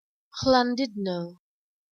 Llandudno Junction(うまく発音できない 「スランディドノ」と言っているようだ ウェールズ語かも)で, バスに乗って, Conwyに行くルートだったが, バス停がどこにあるのか分からない.